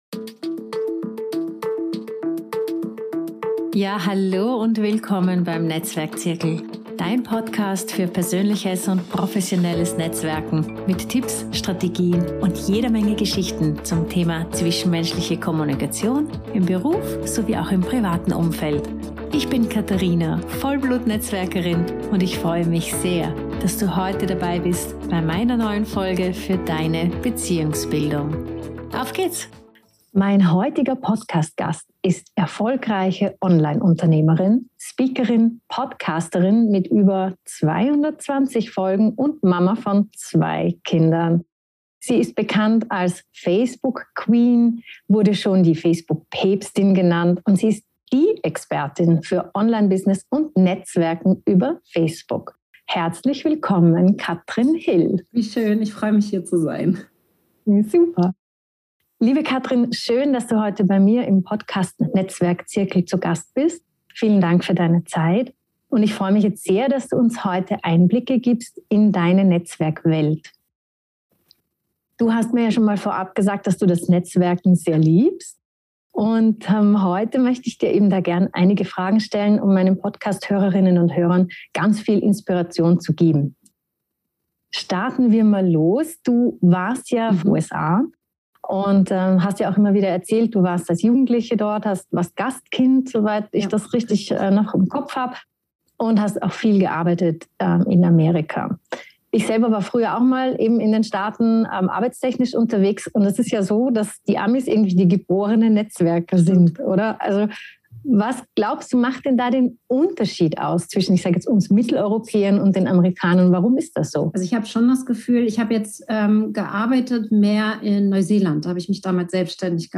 #2: Netzwerken über Facebook: Interview